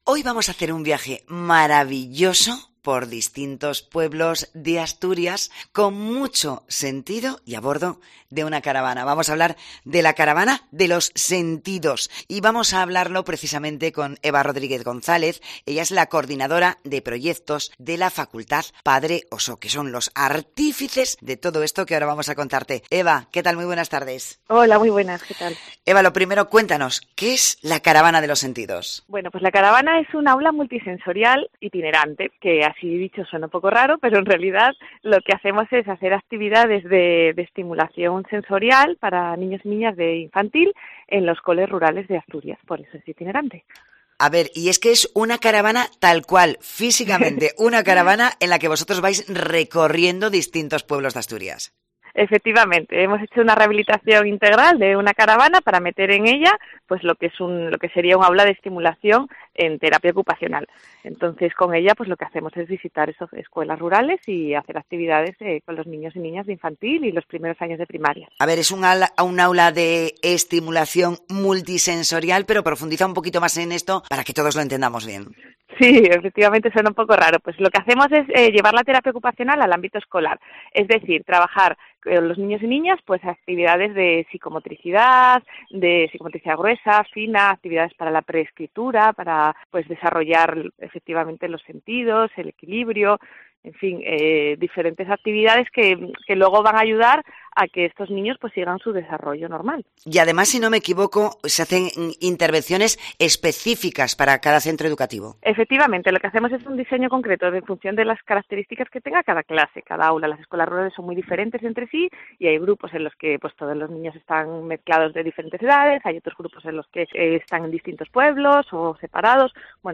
Mediodía COPE Asturias Entrevista